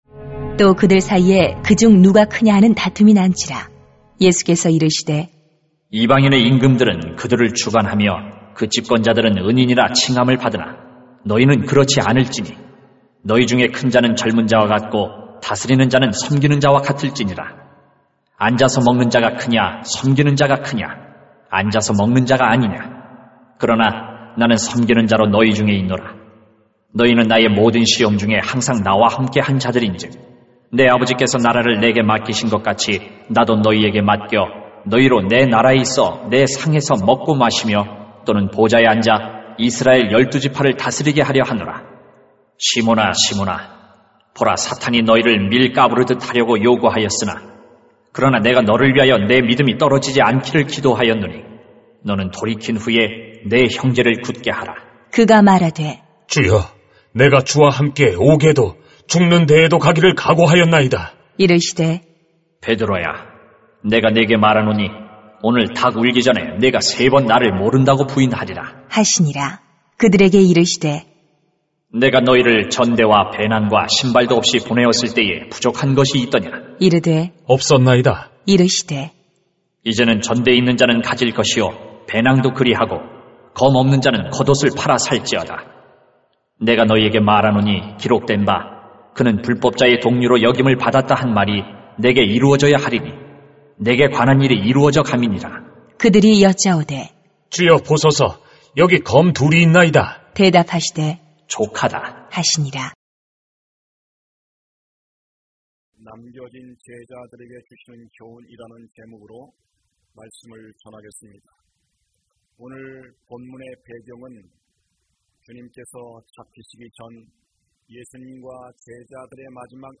[눅 22:24-38] 남겨진 제자들에게 주시는 교훈 > 새벽기도회 | 전주제자교회